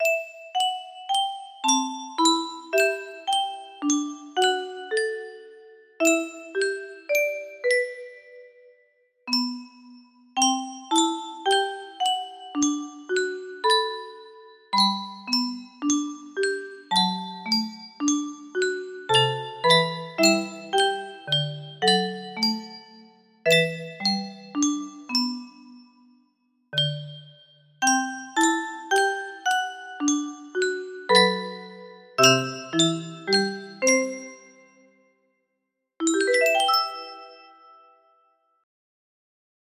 Lupa judulnya music box melody
Full range 60